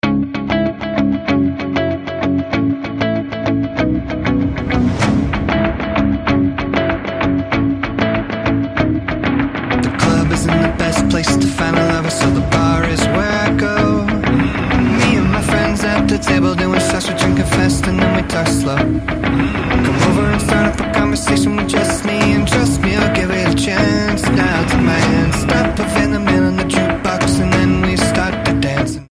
Rock Cover